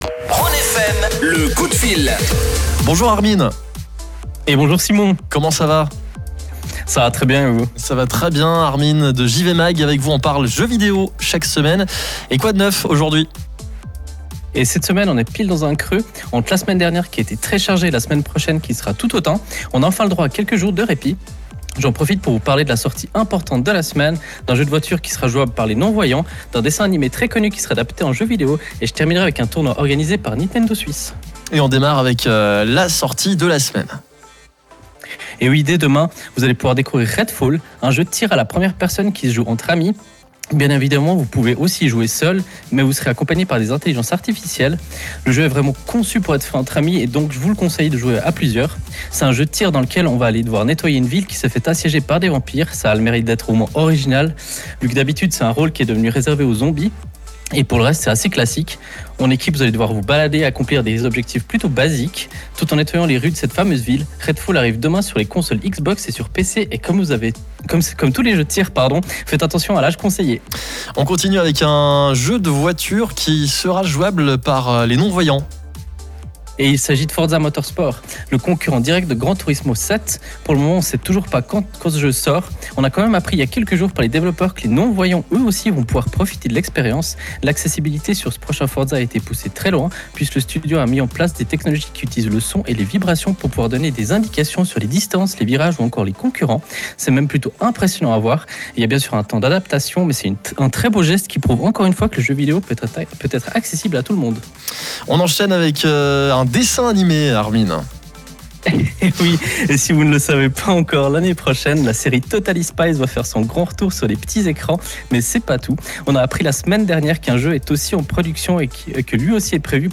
Vous pouvez retrouver le flux du direct juste en dessus.